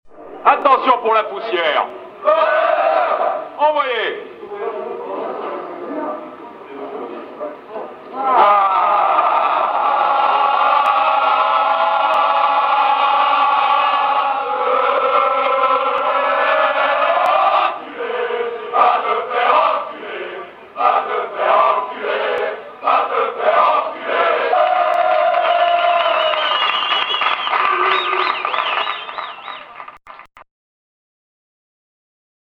« Vaaaaas te faiiirre enculer ! vas te faire enculer, vas te faire enculeeer ! » chantent-ils après avoir trinquer.